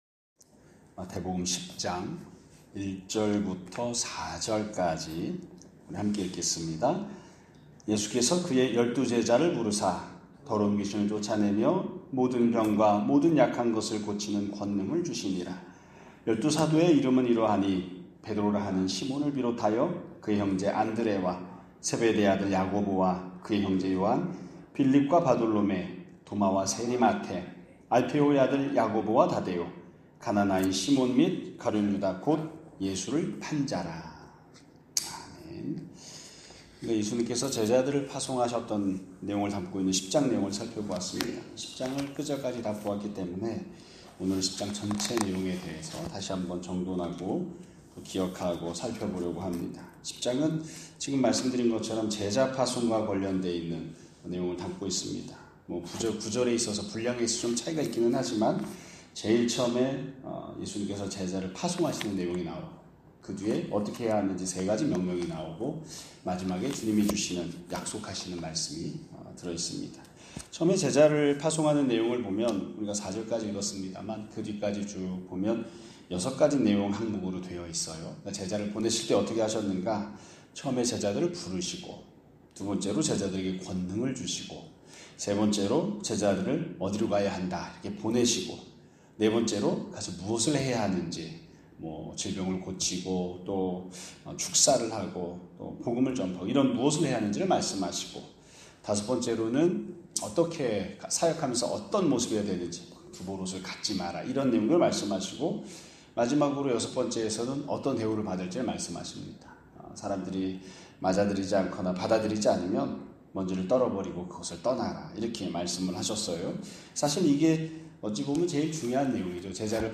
2025년 8월 22일 (금요일) <아침예배> 설교입니다.